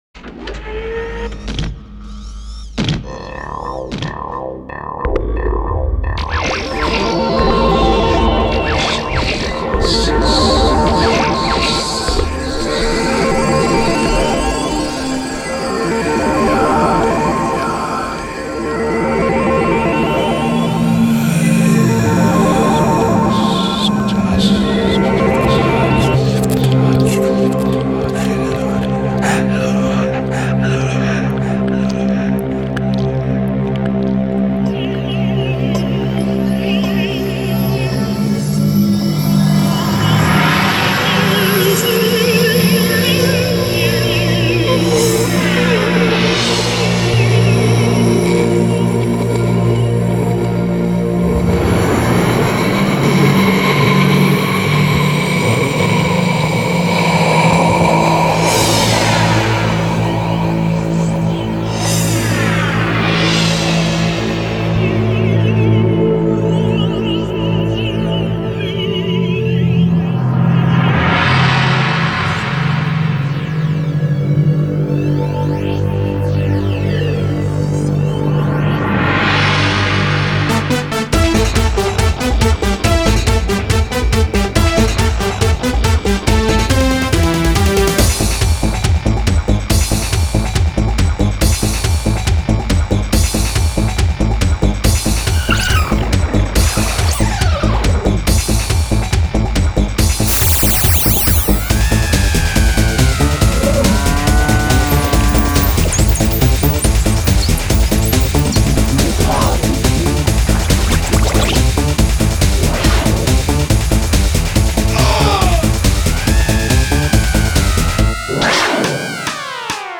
SchouderCom - Zonder zang
Lied-2-Paniek-instrumentaal-.mp3